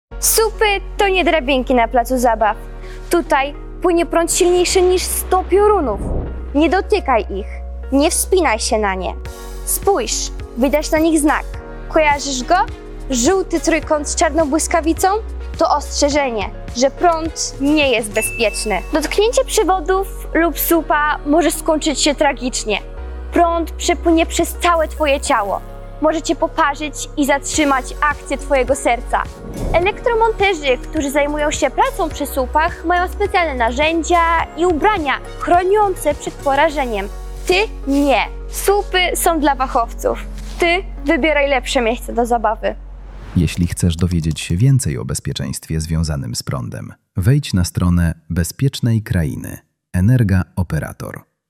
Słupy energetyczne to nie element placu zabaw. Dzieci tłumaczą dzieciom, dlaczego lepiej trzymać się od nich z daleka.